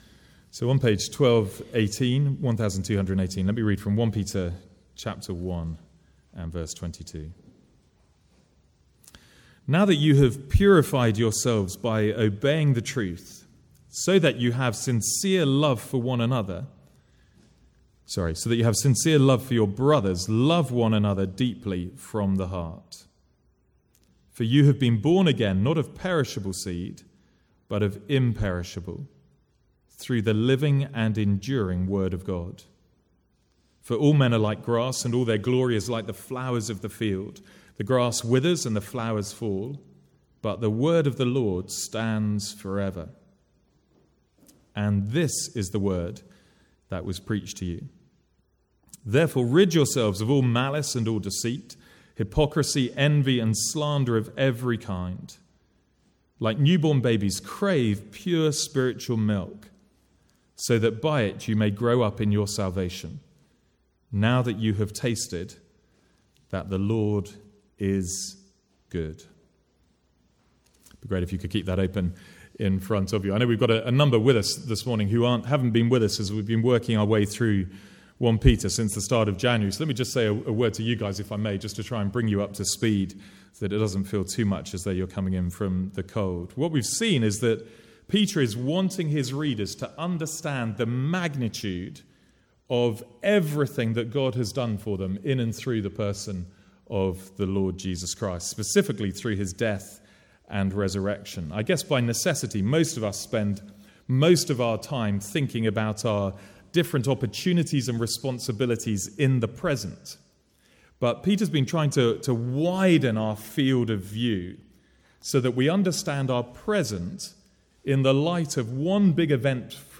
From the Sunday morning series in 1 Peter.